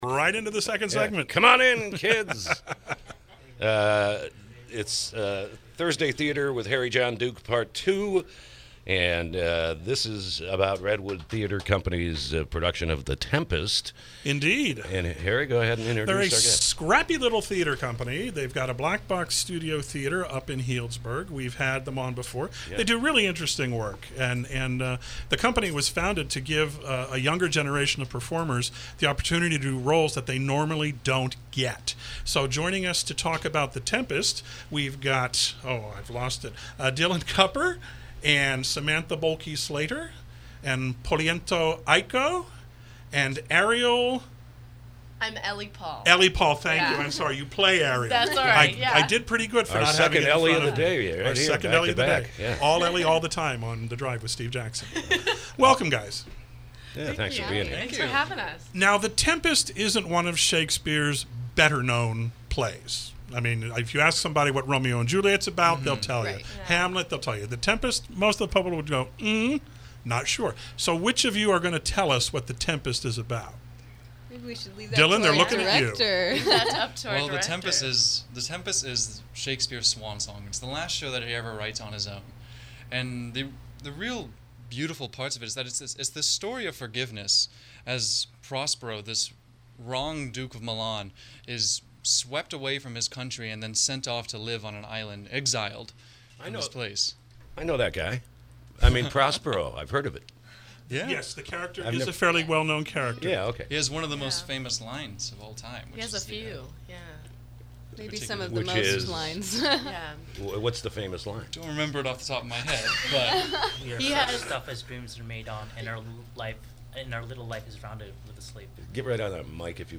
KSRO Interview – “The Tempest”